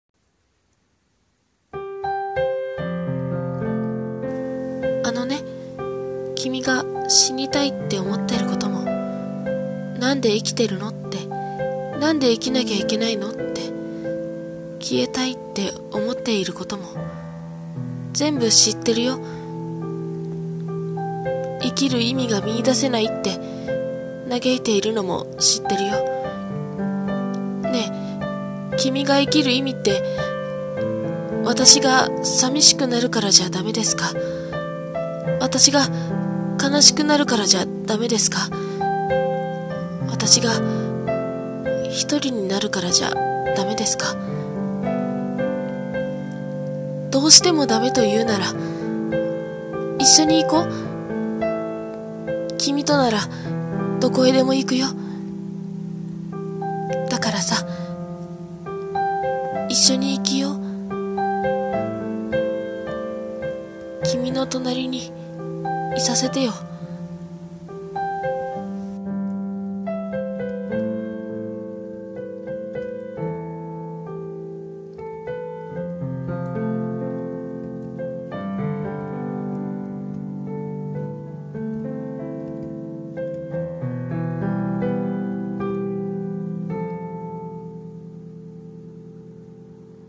声劇：生きる意味